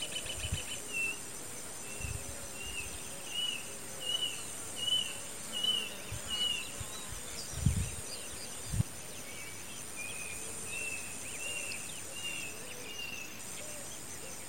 Nothoprocta cinerascens
Nome em Inglês: Brushland Tinamou
Localidade ou área protegida: Las Varillas
Condição: Selvagem
Certeza: Observado, Gravado Vocal